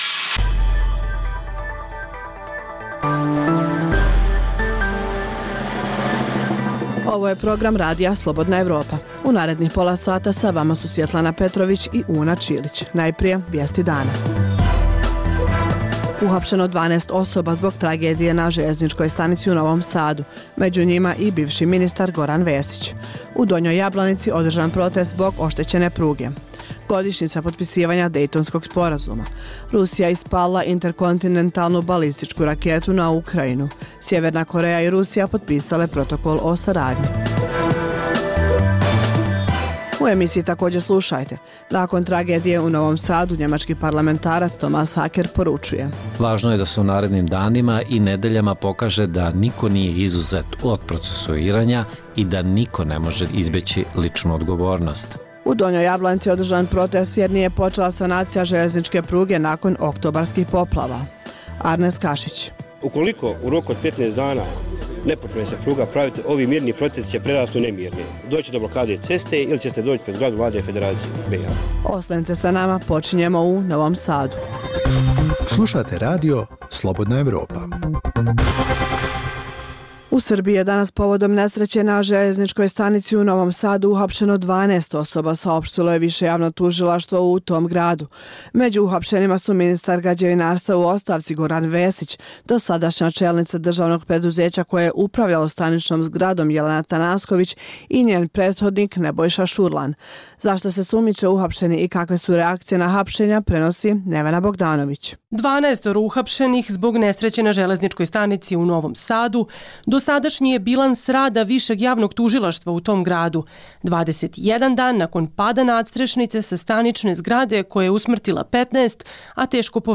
Reportaže iz svakodnevnog života ljudi su svakodnevno takođe sastavni dio “Dokumenata dana”.